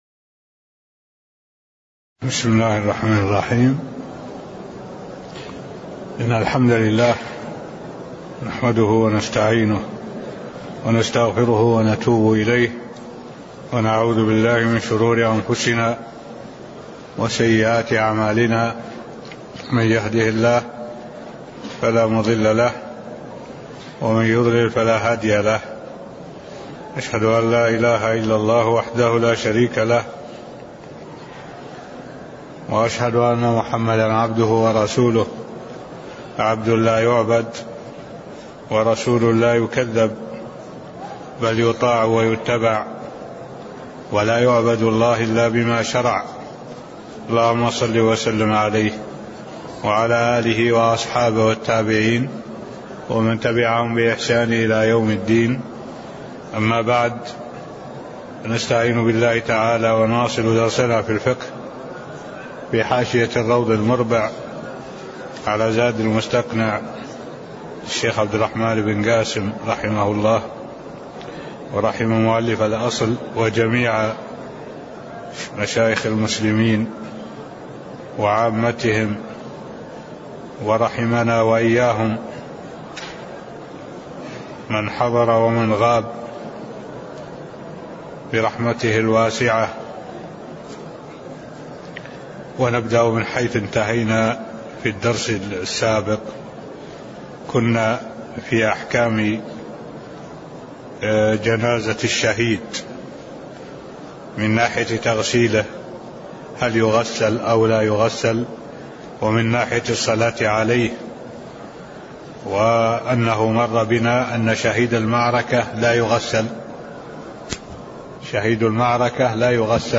تاريخ النشر ٢٤ محرم ١٤٢٩ هـ المكان: المسجد النبوي الشيخ: معالي الشيخ الدكتور صالح بن عبد الله العبود معالي الشيخ الدكتور صالح بن عبد الله العبود جنازة الشهيد (006)